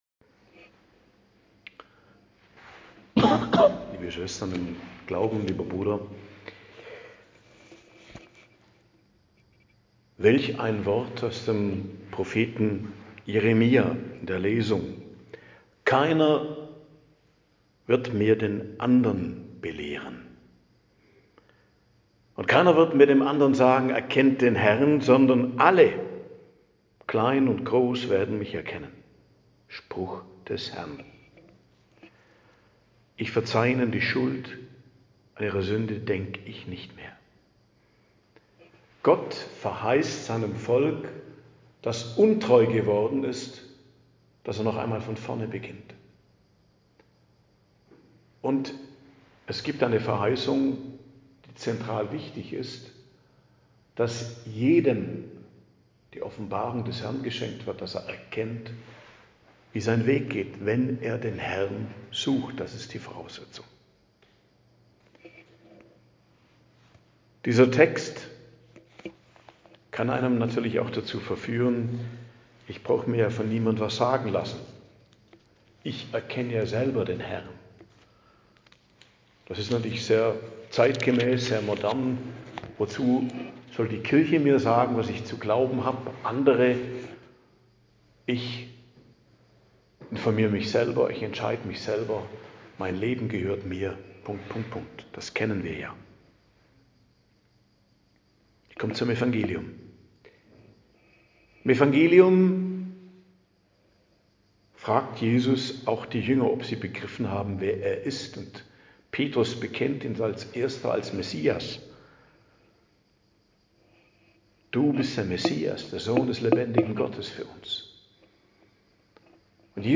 Predigt am Donnerstag der 18. Woche i.J. 8.08.2024